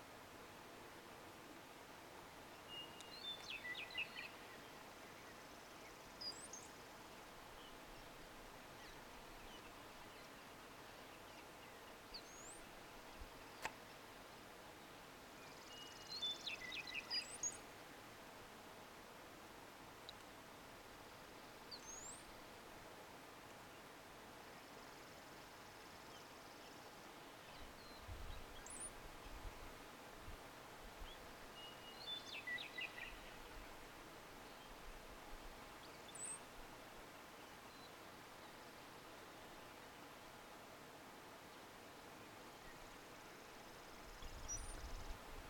Sights and Sounds of Yellowstone in Late Spring/Western Meadowlark
Western meadowlark